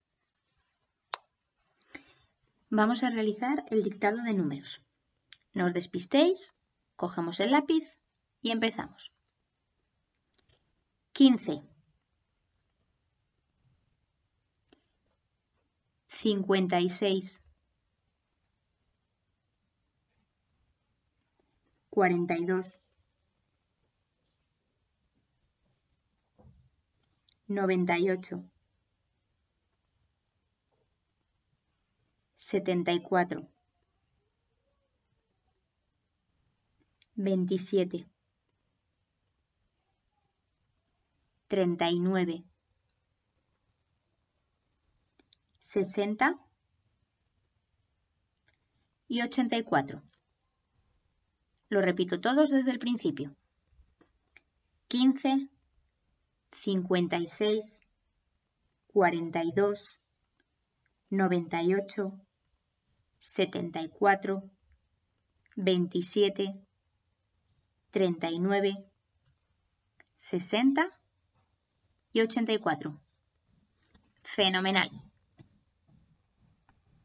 Dictado pág 155
1_Dictado_Matematicas_pag_155.mp3